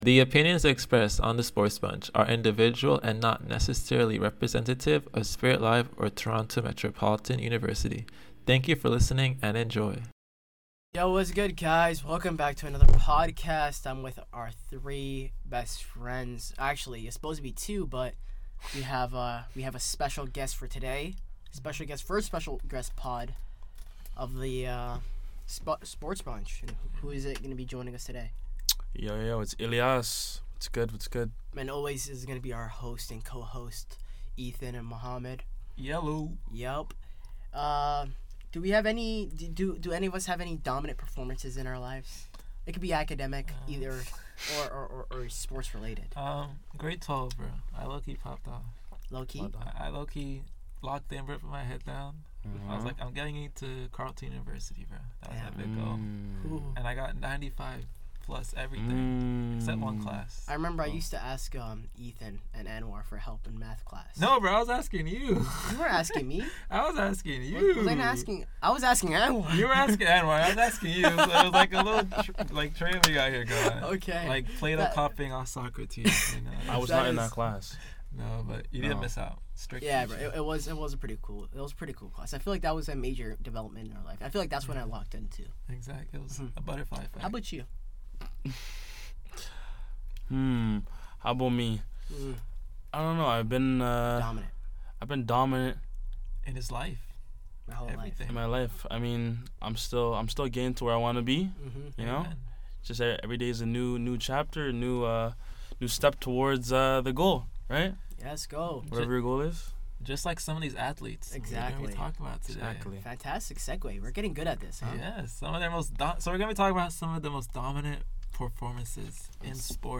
Two friends. One mic.
The SportsBunch is where sports talk gets loud, unfiltered, and fun.
Expect fiery opinions, friendly chaos, and quizzes or games to wrap up each show.